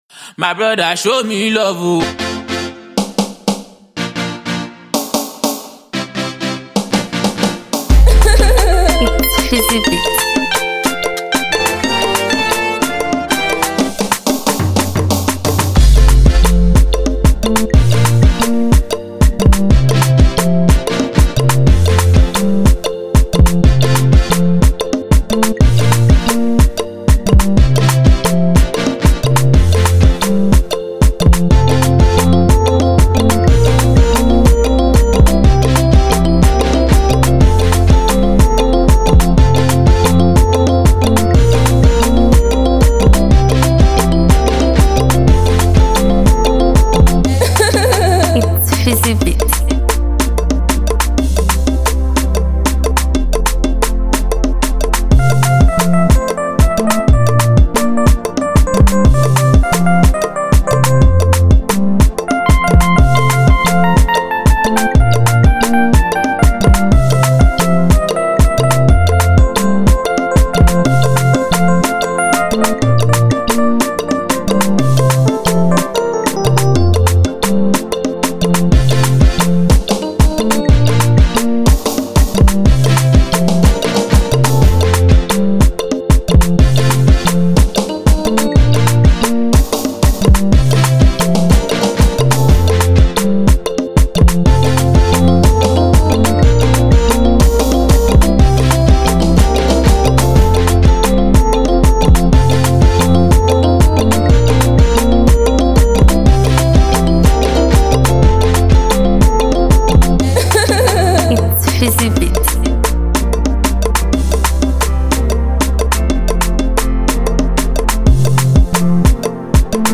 Free beat